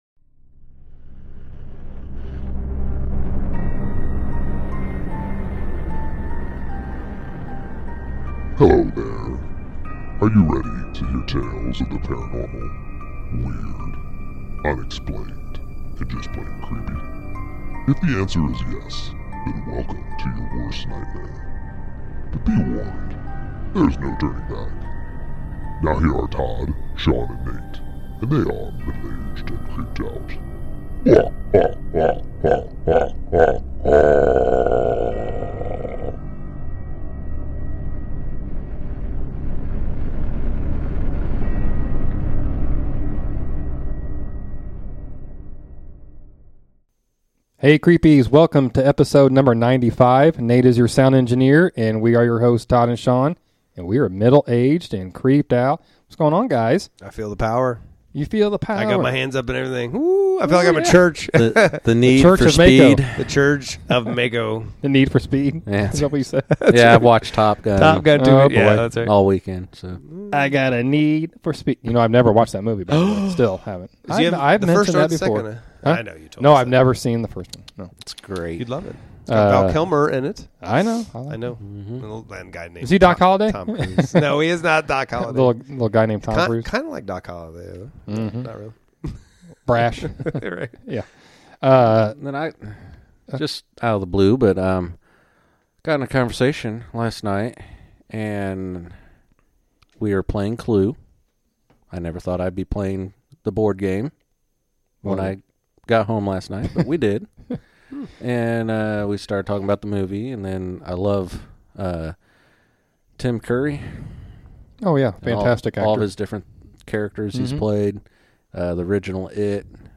Guest Chat